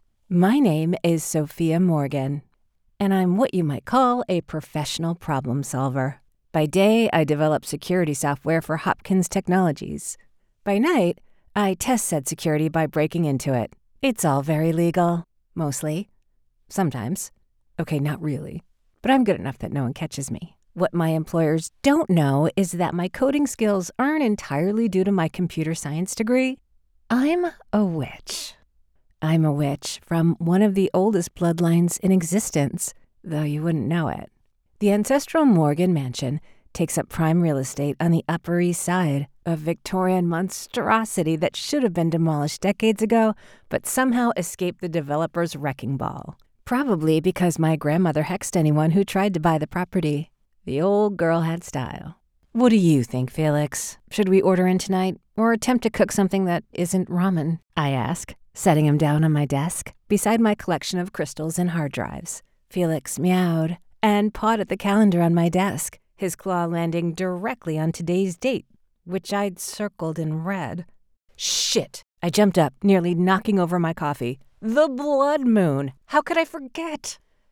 Romance